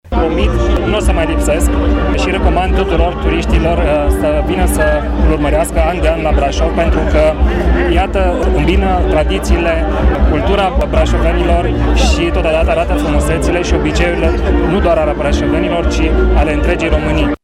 Printre oaspeţii evenimentului, s-a aflat şi ministrul Turismului, Bogdan Trif, care a rămas încântat de cele văzute: